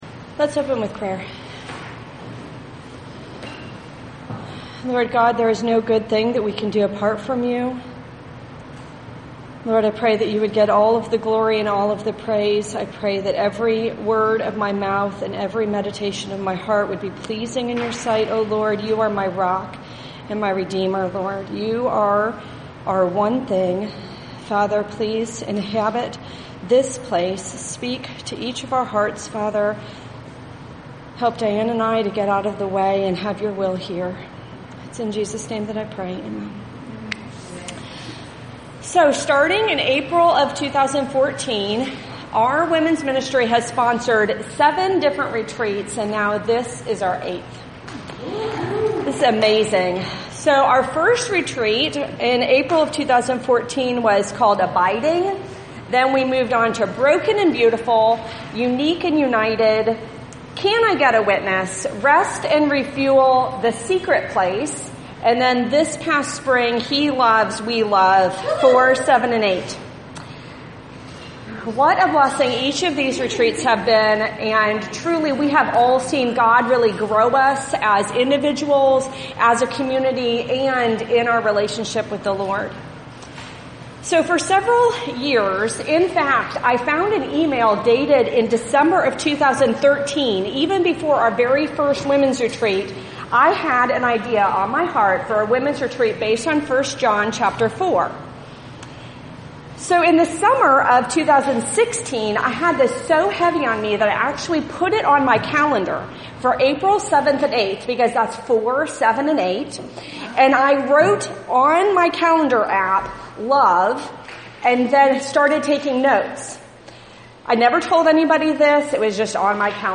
This is an excerpt from a talk my dear friend
and I shared on our church’s ONE THING retreat this fall.